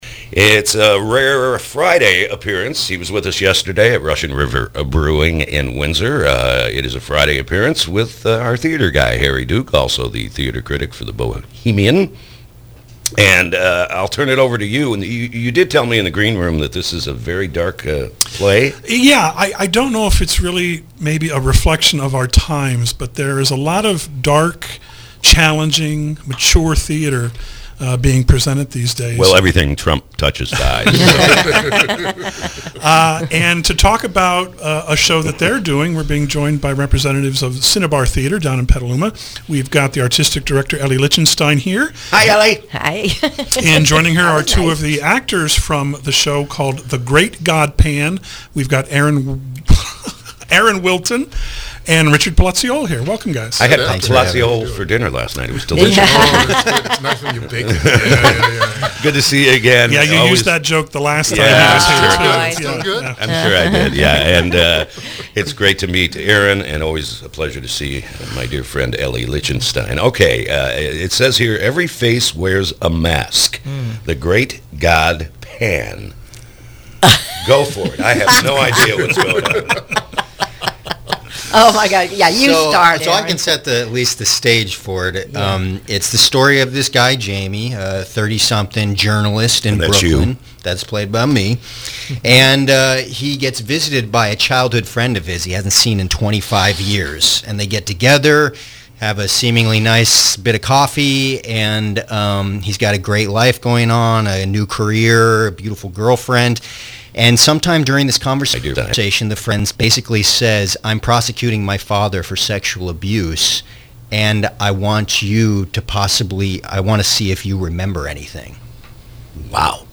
KSRO Interview